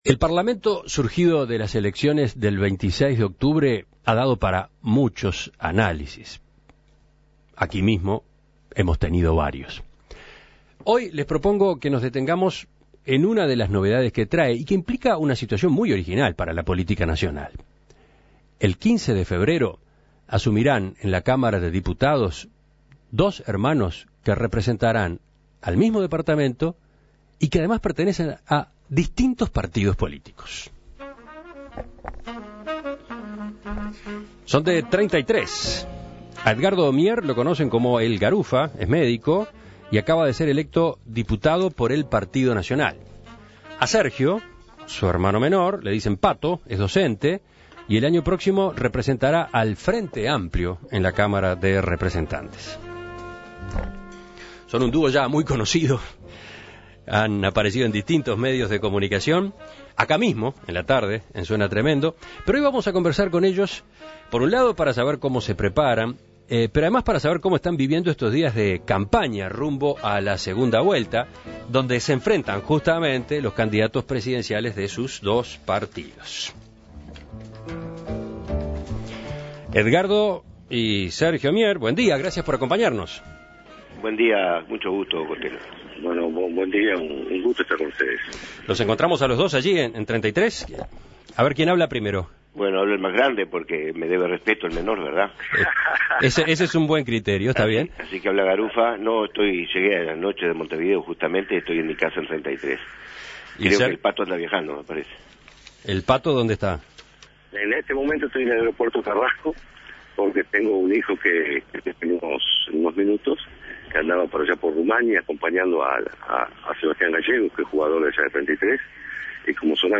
Edgardo Mier y Sergio Mier son hermanos, y serán los representantes departamentales en el Parlamento. La particularidad es que no pertenecen al mismo partido: Edgardo es del Partido Nacional y Sergio, del Frente Amplio. En Perspectiva conversó con los dos para conocer sus inicios en política, cómo será la vida familiar luego de que ambos asuman sus bancas, sus diferencias y coincidencias en temas de política.